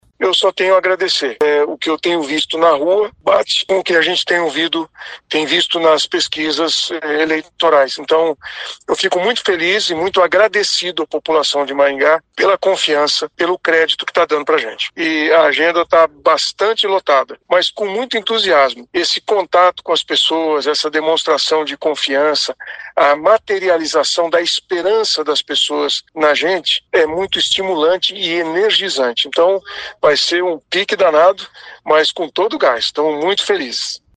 Ouça o que diz o candidato Silvio Barros sobre o resultado da pesquisa: